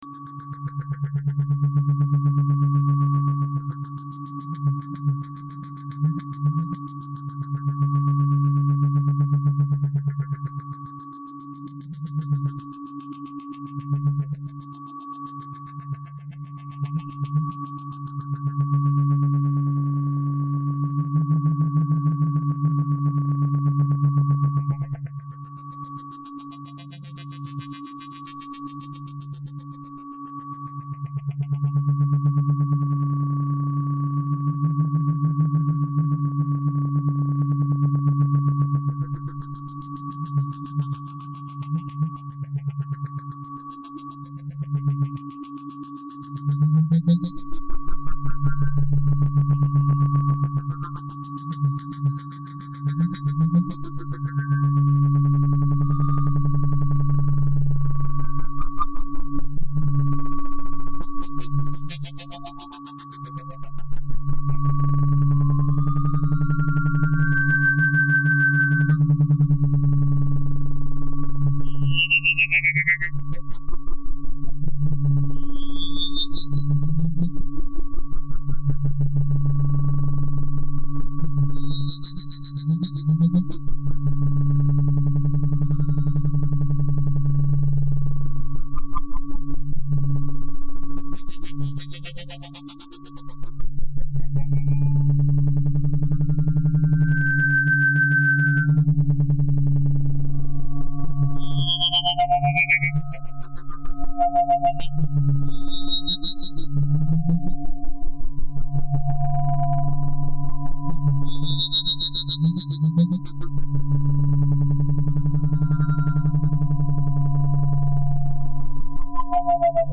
area di azione musicale elettronica popolare sperimentale